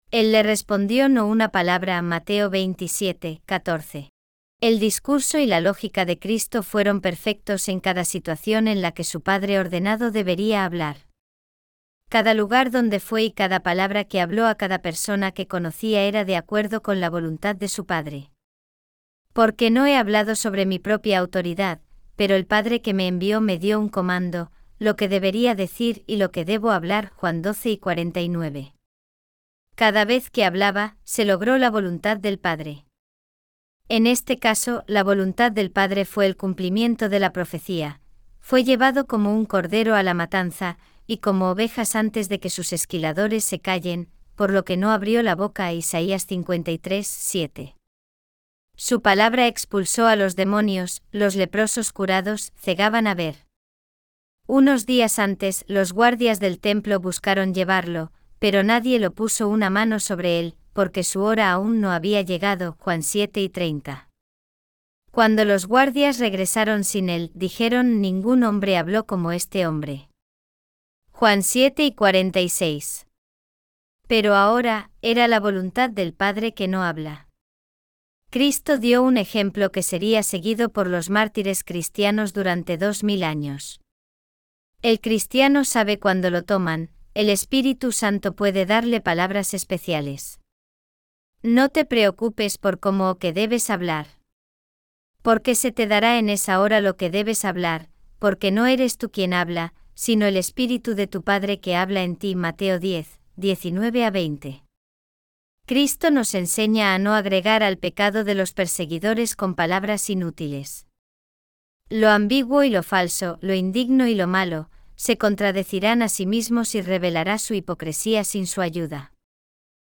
April 2 Morning Devotion